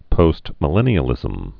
(pōstmə-lĕnē-ə-lĭzəm)